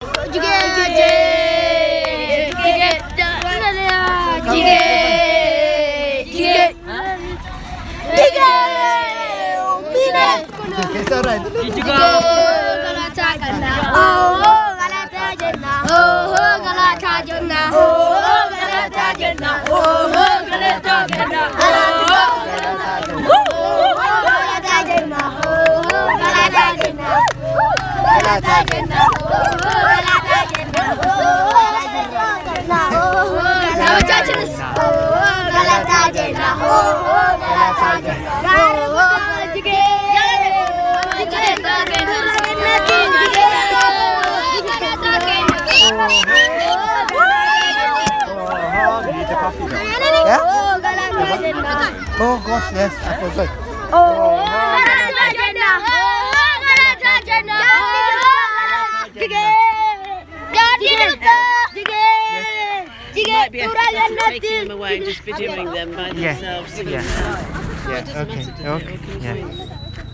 Shakiso st children singing